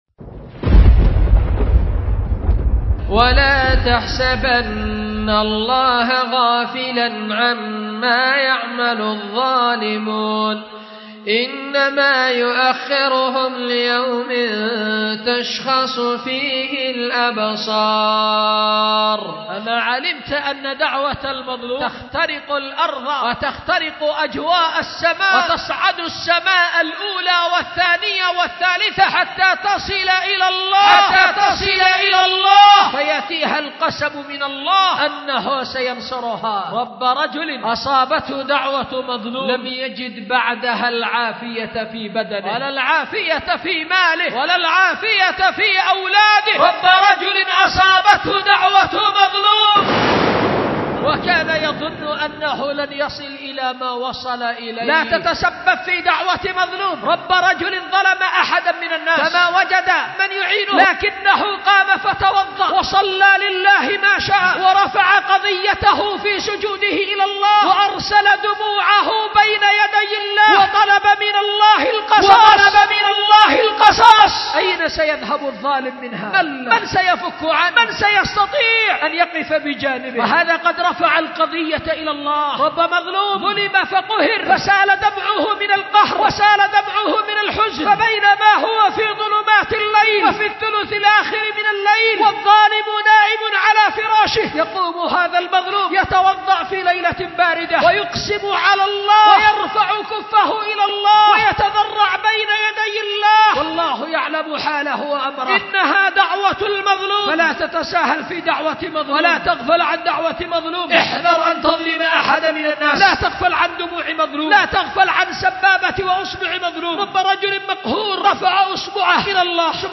خطبة
أُلقيت بدار الحديث للعلوم الشرعية بمسجد ذي النورين ـ اليمن ـ ذمار